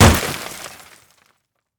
transport
Car Window Smash 3